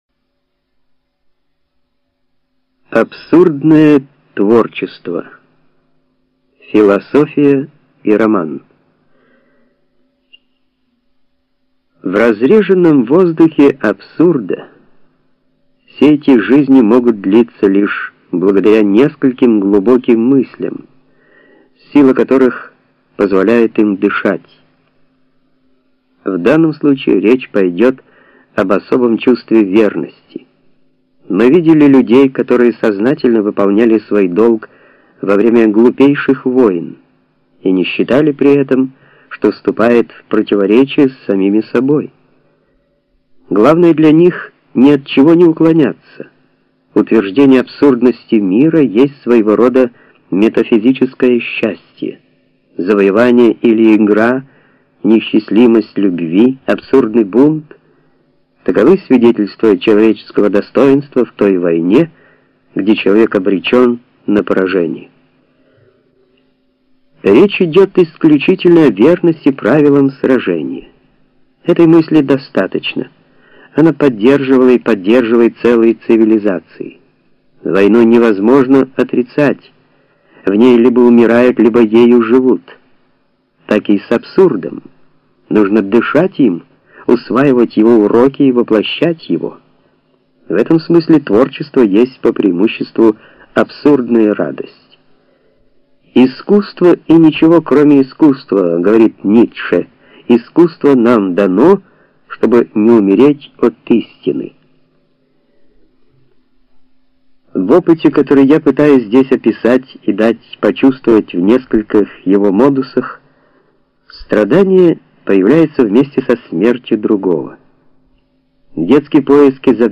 Скачать аудио книгу Миф о Сизифе Камю, Скачать аудио книгу бесплатно Миф о Сизифе Камю